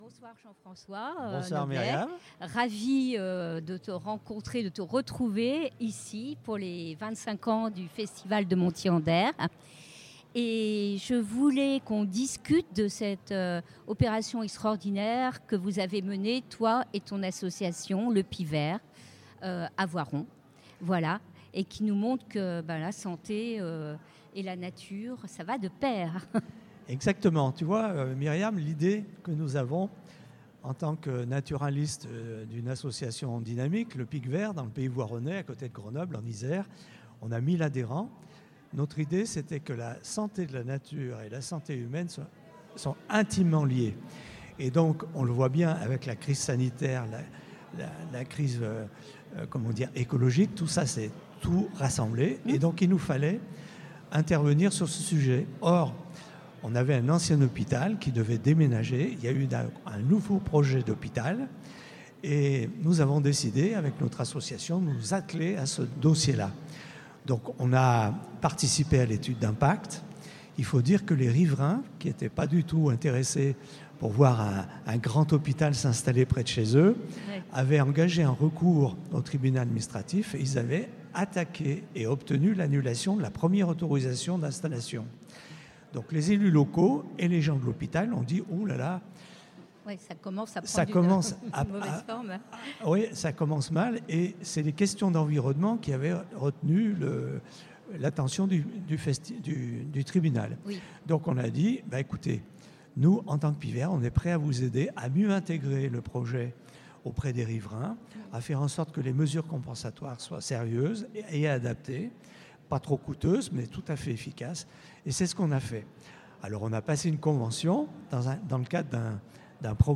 Du 17 au 20 novembre, les JNE ont été présents au Festival photo nature de Montier-en-Der, participant à des conférences et des débats, et intervenant sur la radio du Festival.